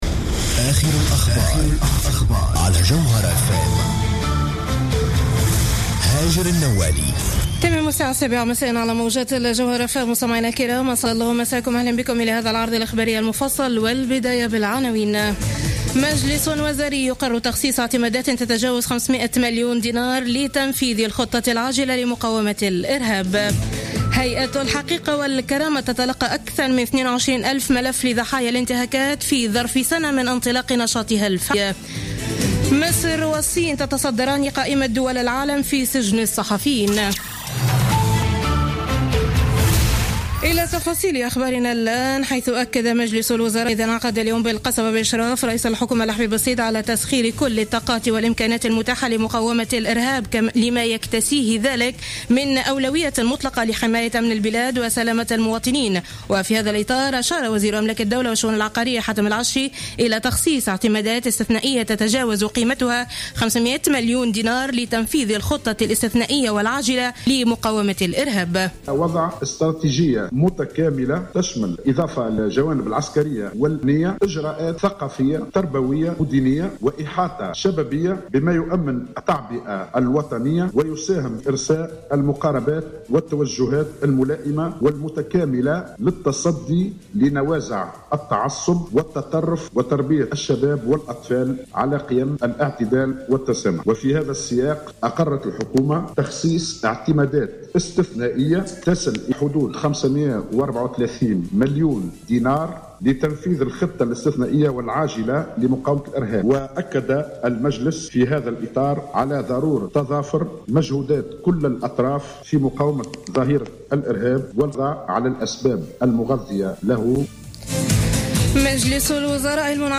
نشرة أخبار السابعة مساء ليوم الأربعاء 16 ديسمبر 2015